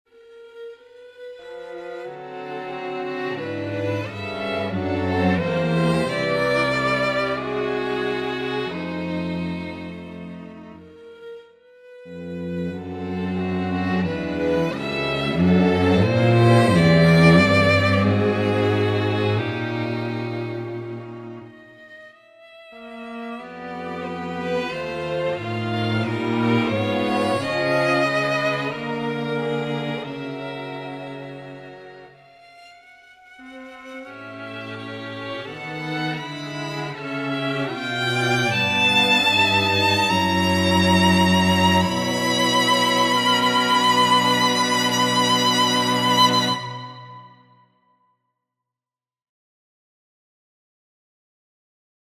Persichetti Exercise 3 - 51 for String Quartet